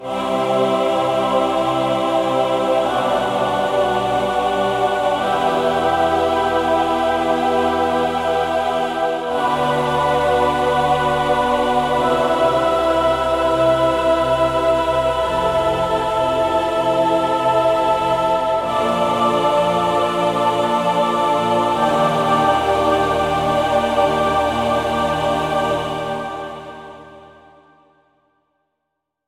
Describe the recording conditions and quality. Optimised for the M3, and powered by the same recording sessions I did for the huge OASYS ASSAULT library.